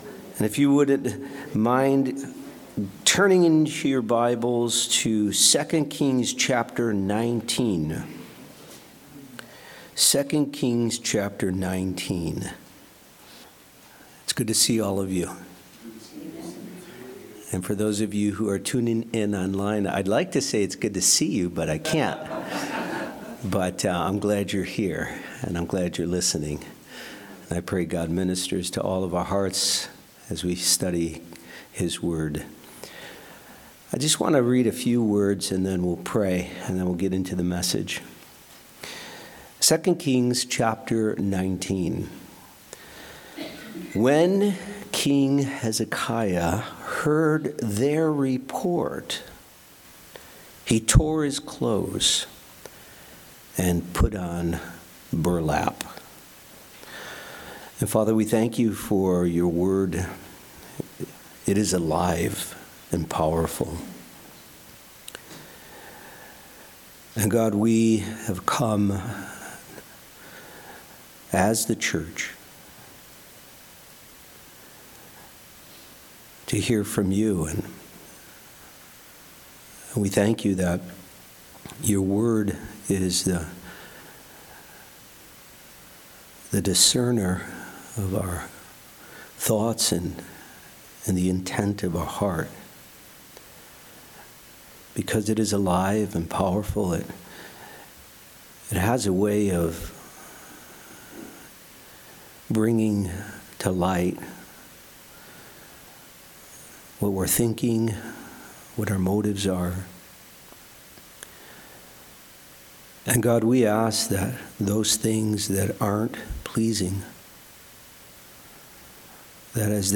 Check out the most recent bible study message or use the tools provided to browse our archives of Topics, Speakers, or books of the Bible.